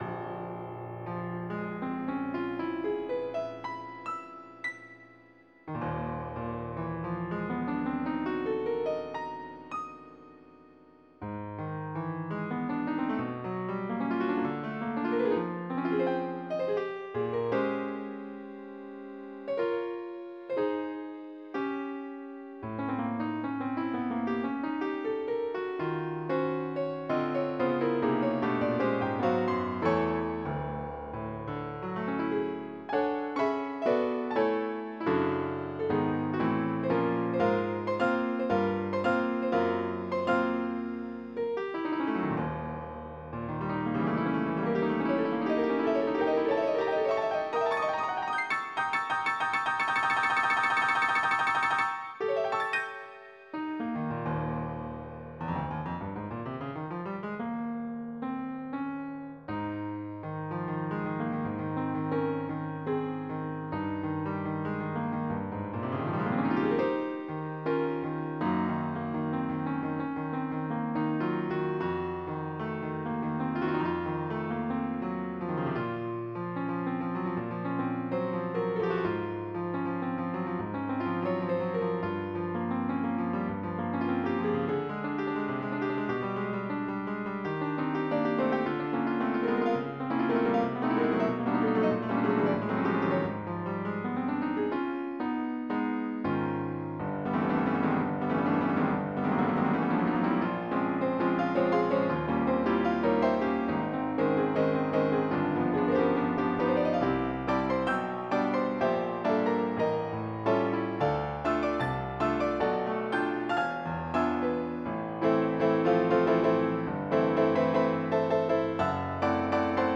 3-2 打ち込みによる演奏　 3
Veloc65.MIDをmp3に変換